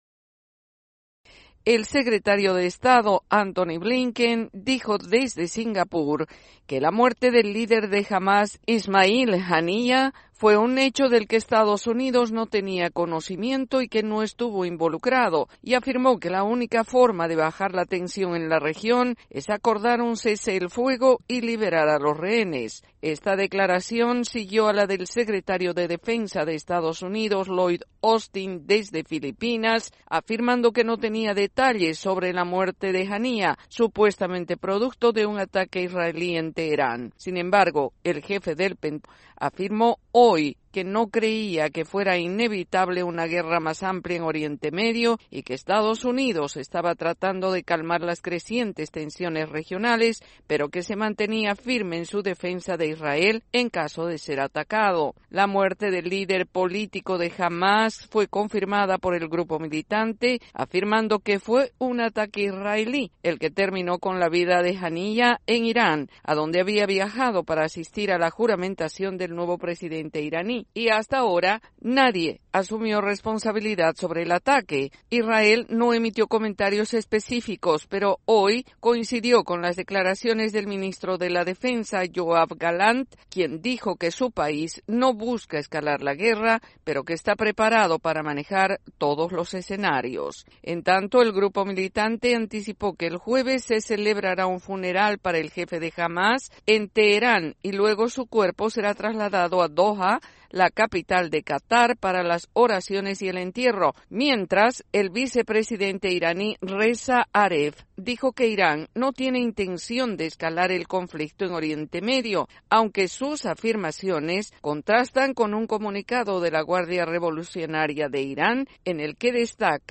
AudioNoticias
desde la Voz de América en Washington DC.